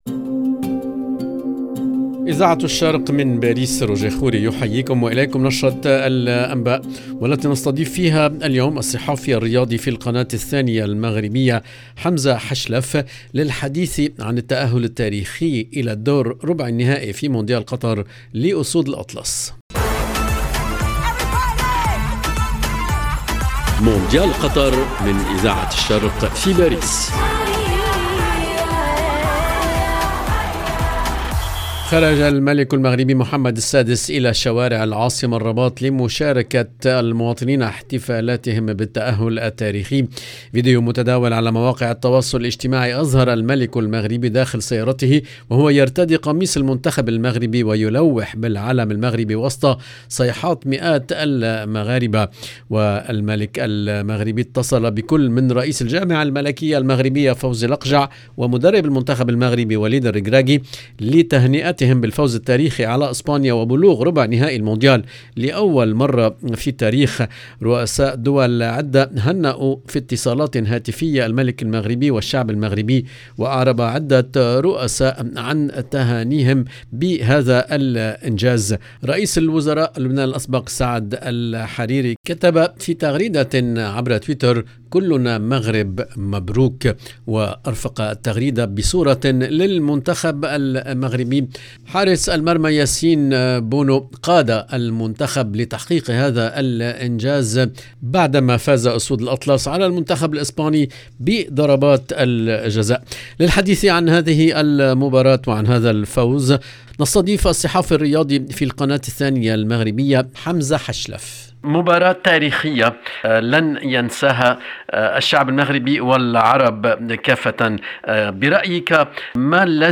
EDITION DU JOURNAL DU SOIR EN LANGUE ARABE DU 7/12/2022